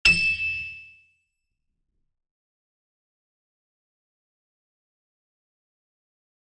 HardAndToughPiano
e6.mp3